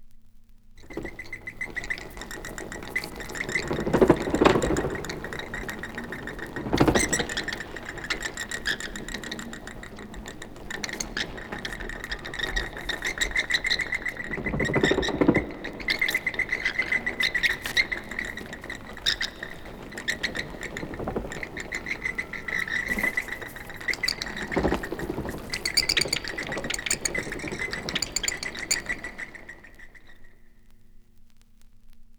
• bats squeaking and flying.wav
bats_squeaking_and_flying_njU.wav